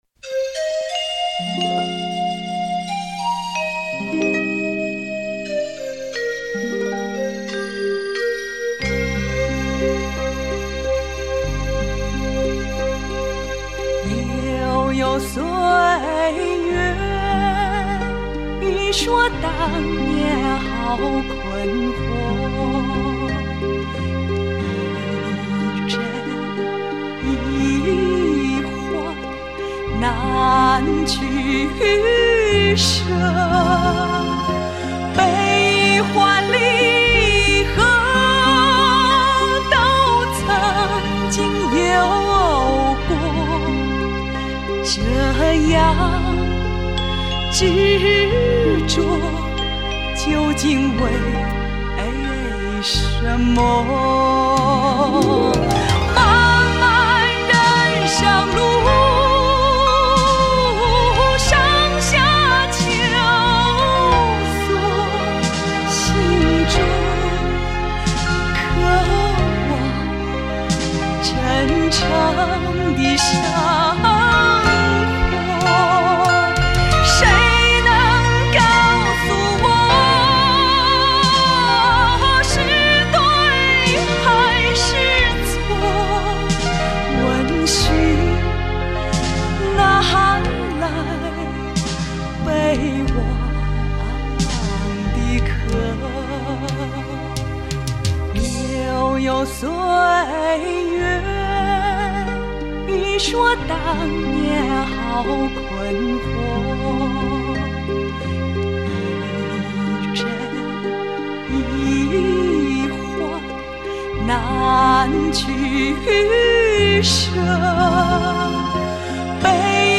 她的声音宽厚、高亢，深沉通畅；她的演唱气度雍容，婉转自如，具有东方女性温柔、典雅的魅力。